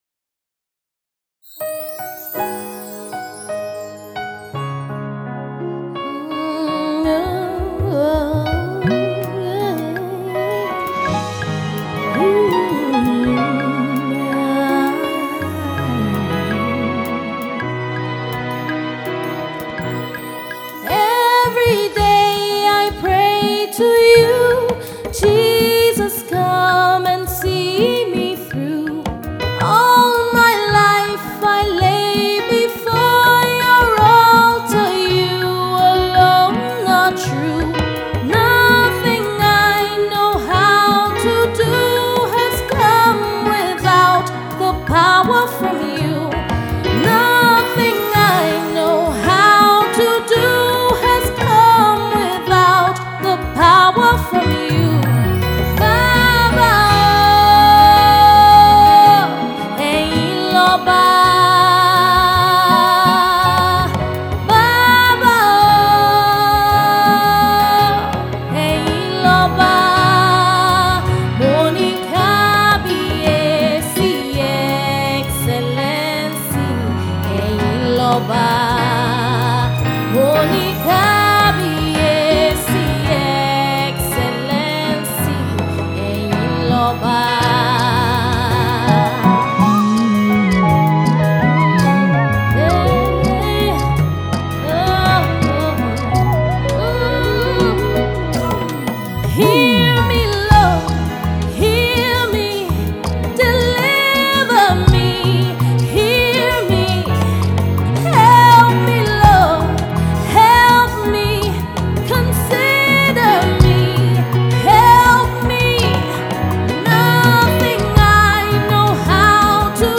is a lovely ballad